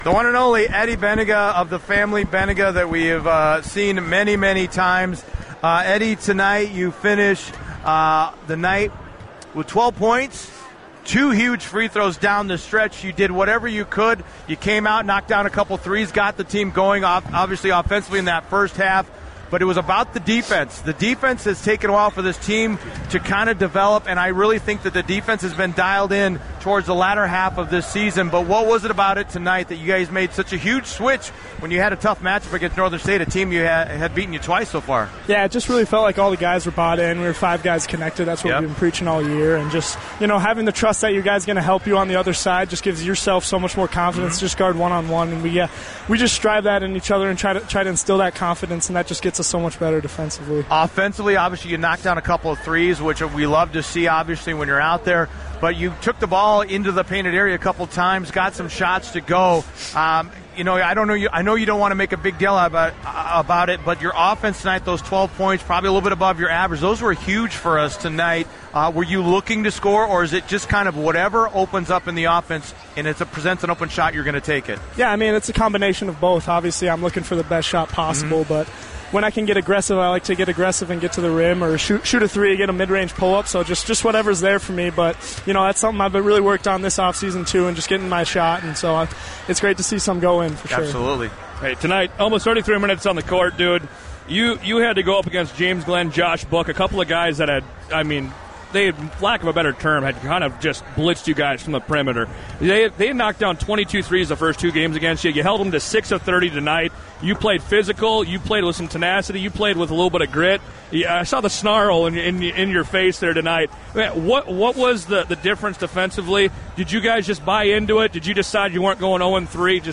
Postgame Interview | The Flag - AM 1100 and FM 92.3 WZFG